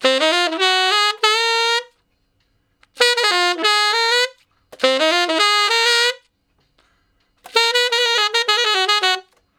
068 Ten Sax Straight (Ab) 03.wav